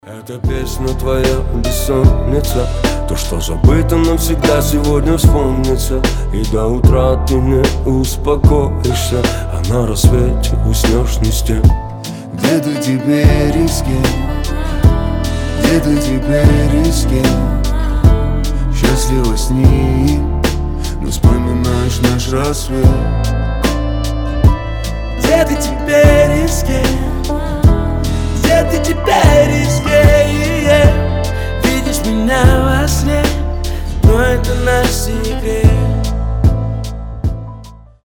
• Качество: 320, Stereo
мужской вокал
лирика
спокойные